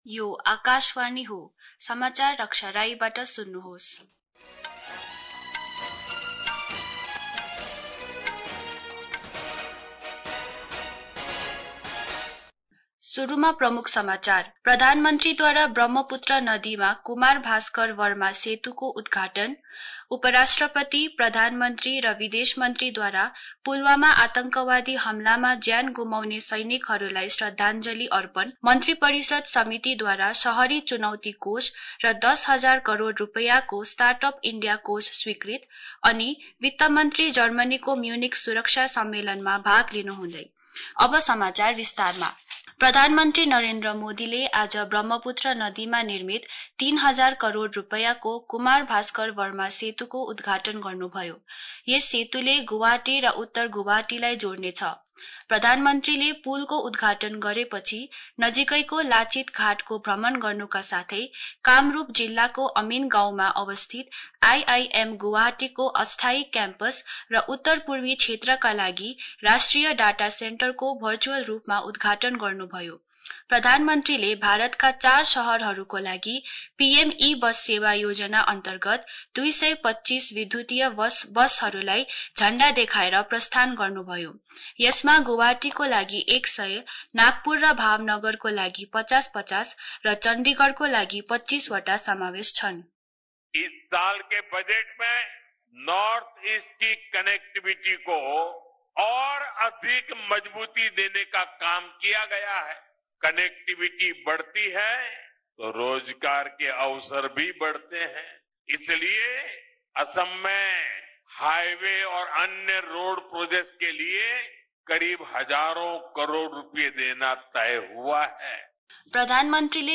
शहरी बुलेटिंस